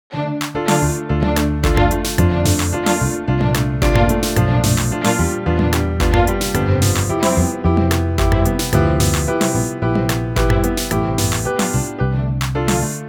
On creating a snippet of an audio file with multiple instruments (again on GarageBand), we were able to create a similar pitch against time visualization, this time for all the instruments in the audio file.
Multiple_Instruments.wav